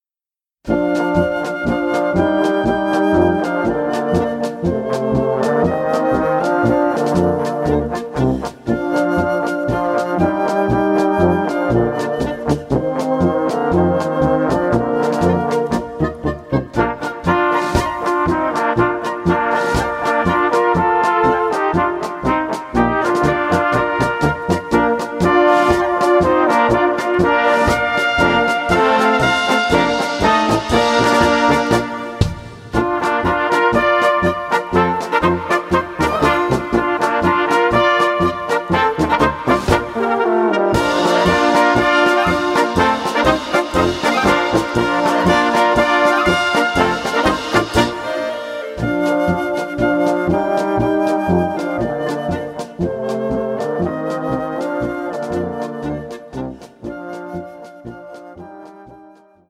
Polka für Blasorchester…